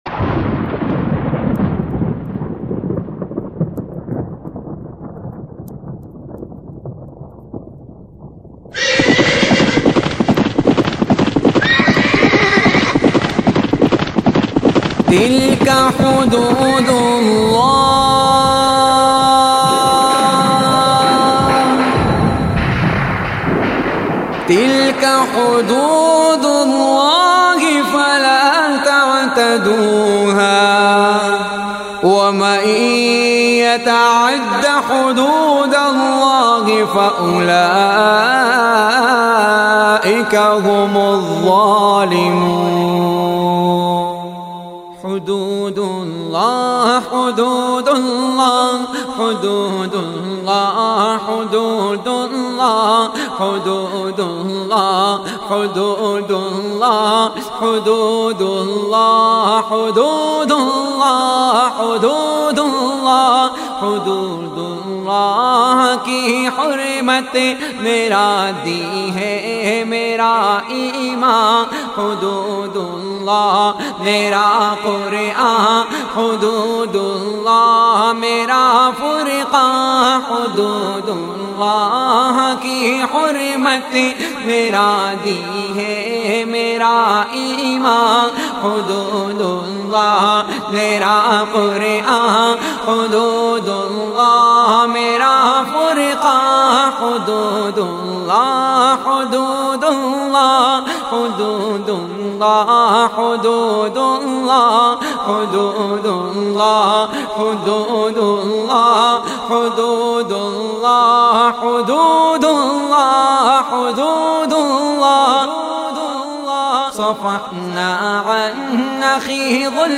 Heart-Touching Voice
Naat khwans